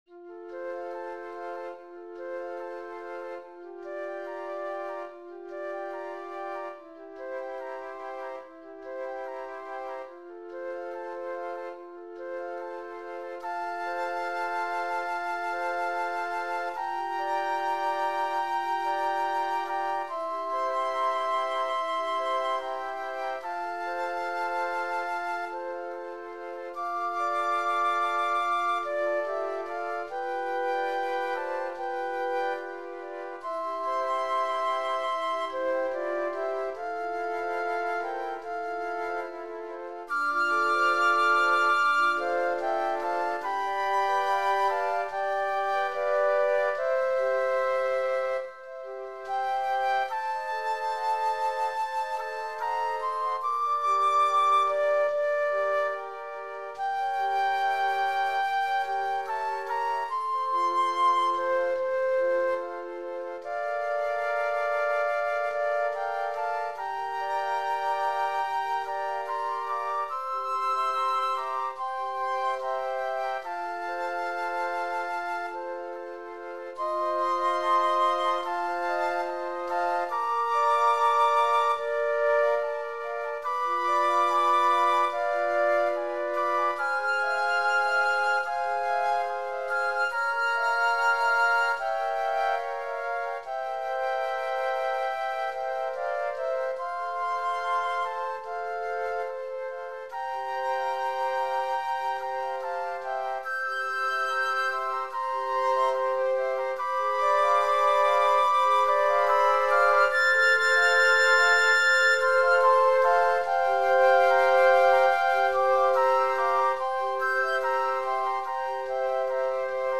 Voicing: Flute Sextet